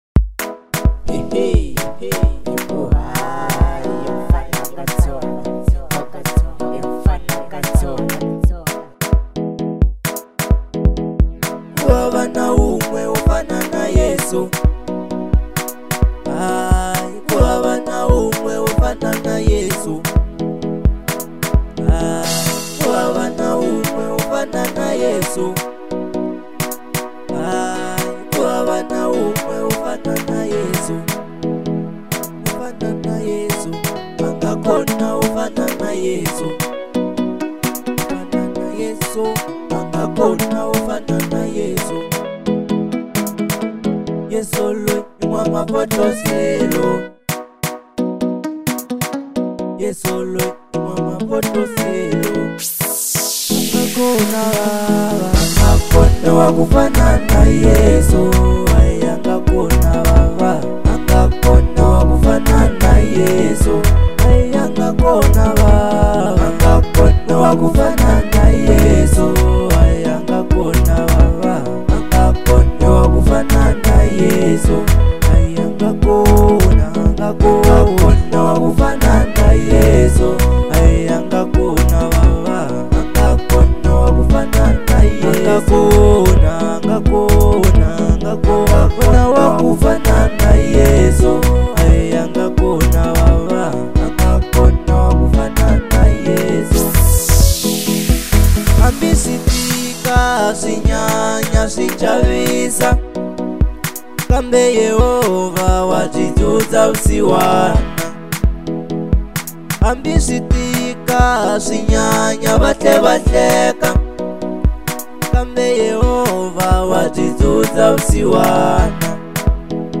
05:45 Genre : Marrabenta Size